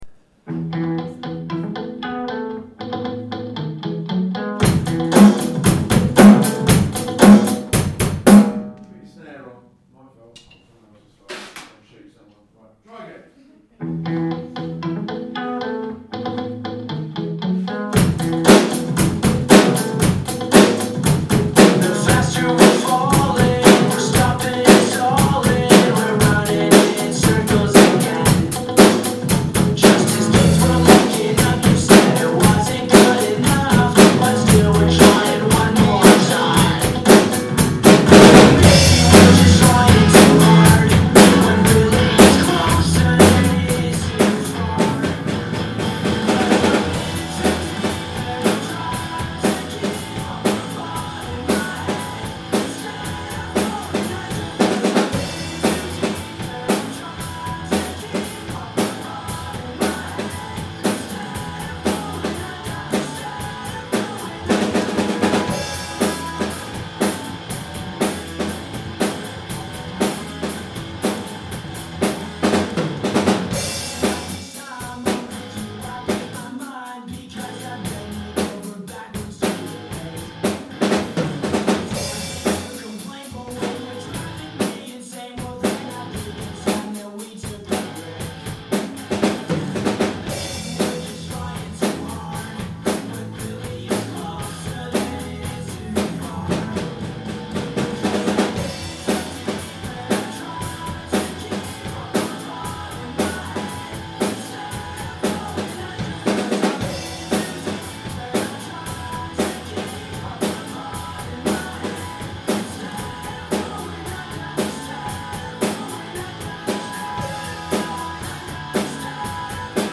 loud drums